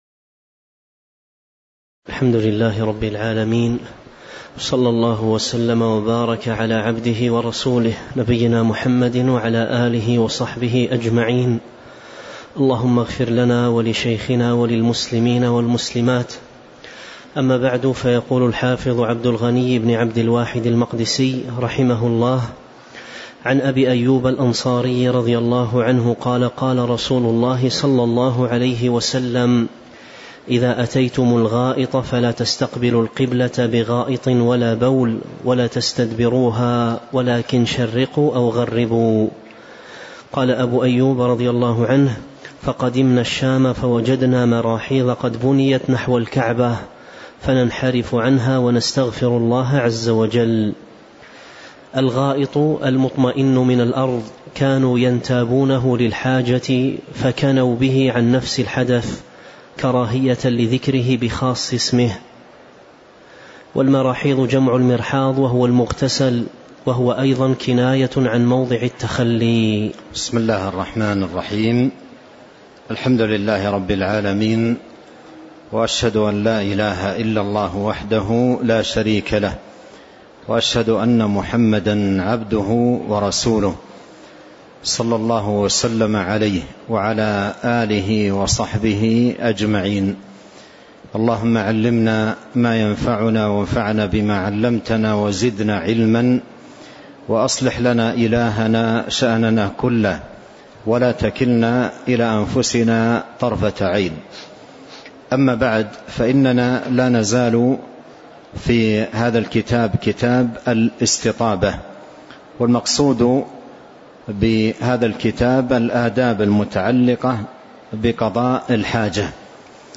تاريخ النشر ٨ ربيع الأول ١٤٤٤ هـ المكان: المسجد النبوي الشيخ